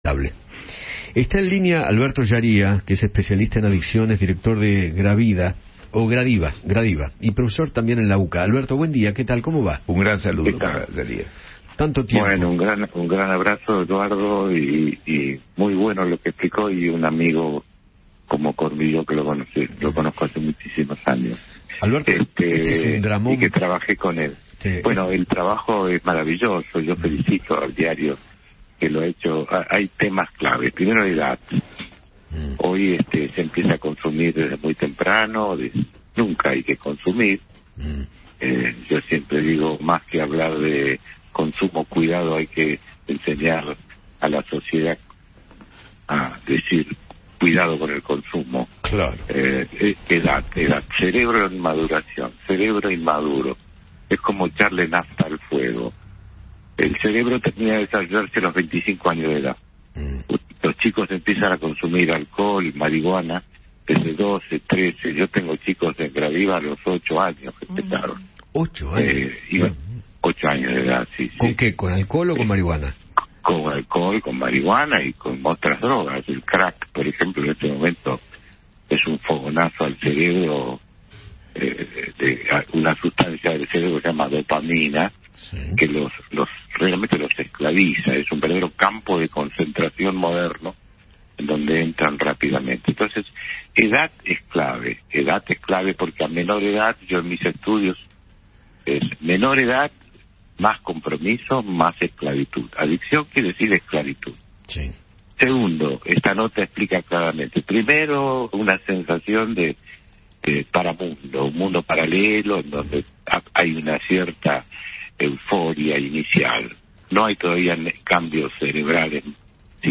habló con Eduardo Feinmann